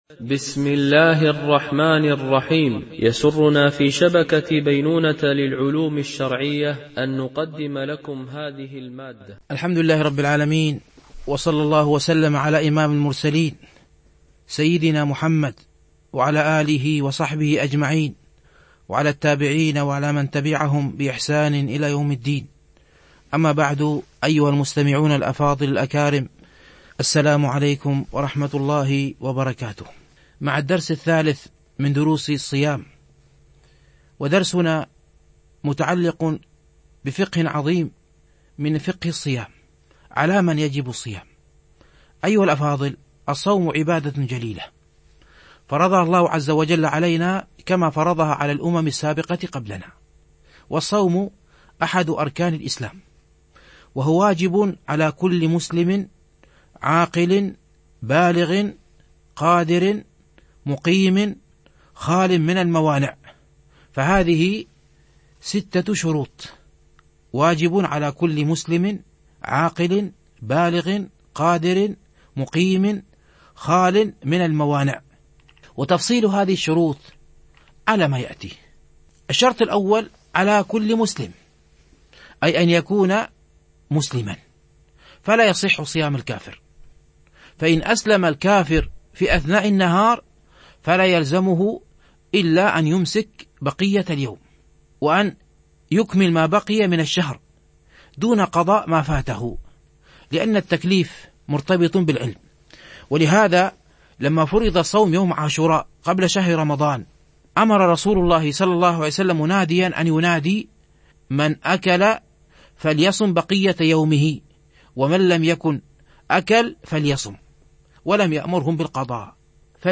فقه الصائم - الدرس 3